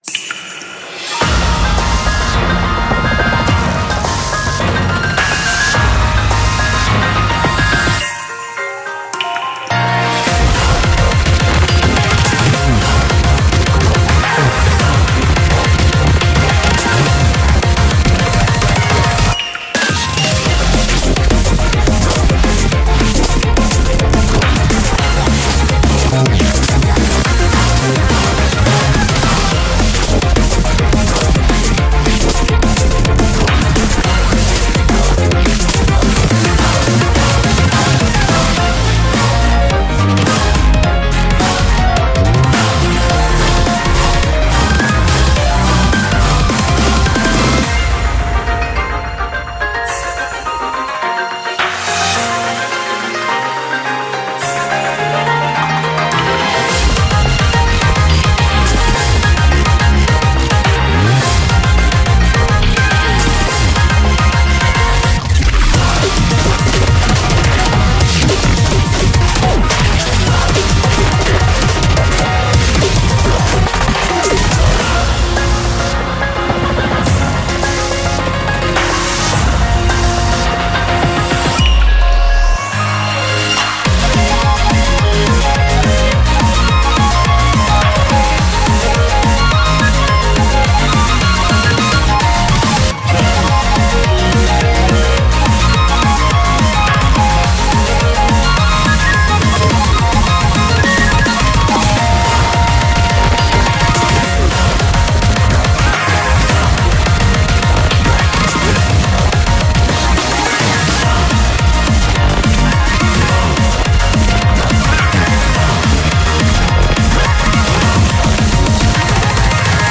BPM106-212
Audio QualityPerfect (Low Quality)